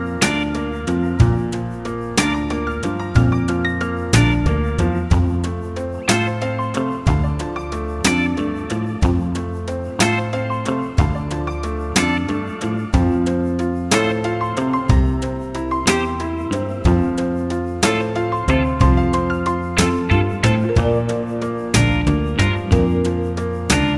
no Backing Vocals Country (Female) 2:59 Buy £1.50